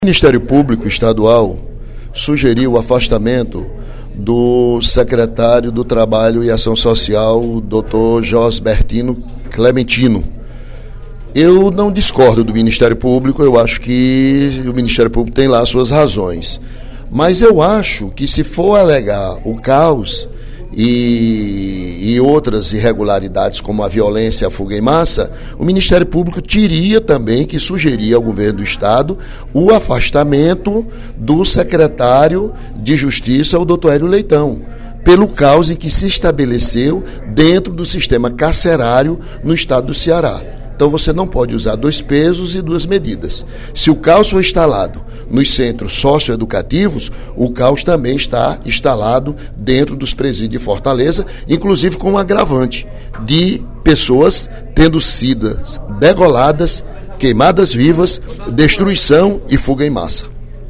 O deputado Ely Aguiar (PSDC) sugeriu, no primeiro expediente da sessão plenária desta sexta-feira (03/06), ao Ministério Público do Ceará que solicite o afastamento do secretário de Justiça e Cidadania do Ceará, Hélio Leitão.